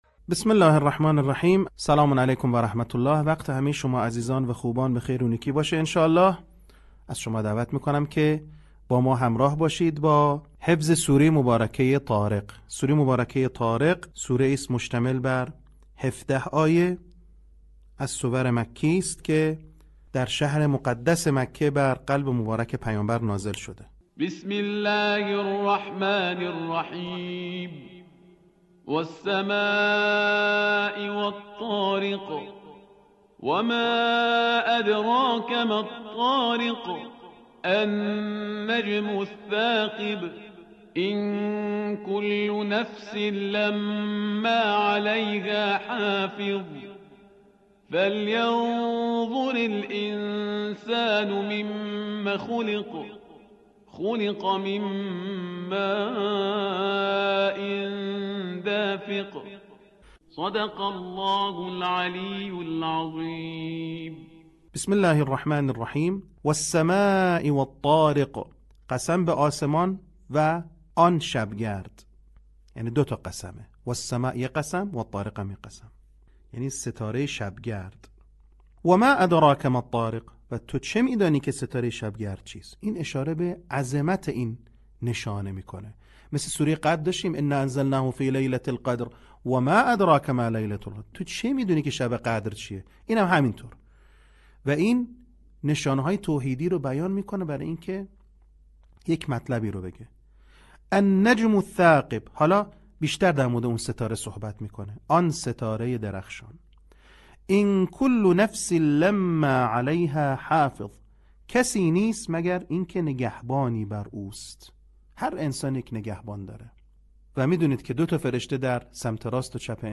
صوت | آموزش حفظ سوره طارق